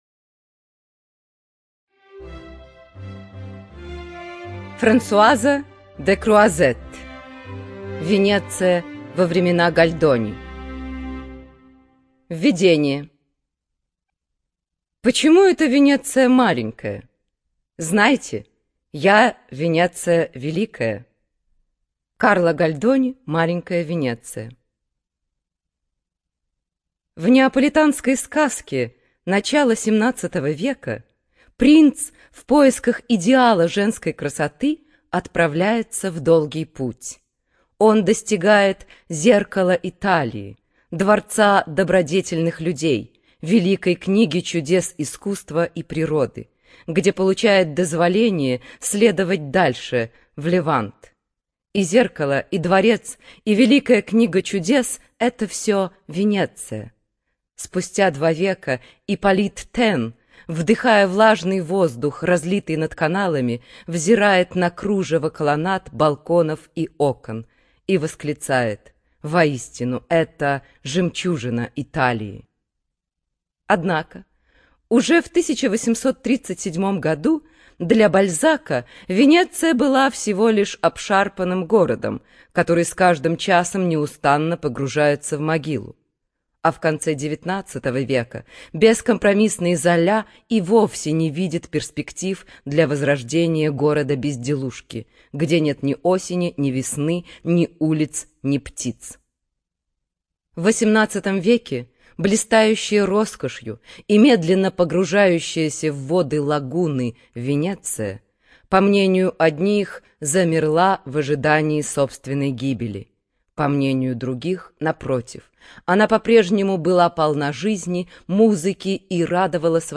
Студия звукозаписиСидиком